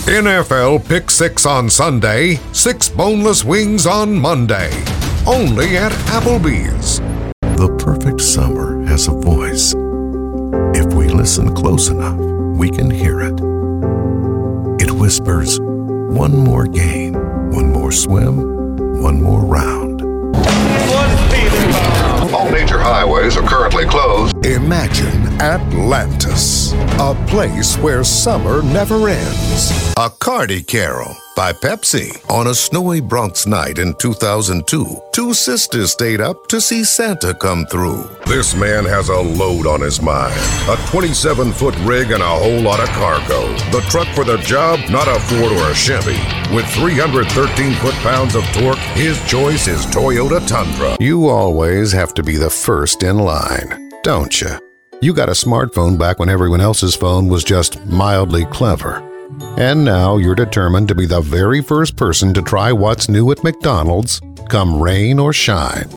Inglés (internacional)
Inglés (Estados Unidos)
Confiable
Autoritario
Oscuro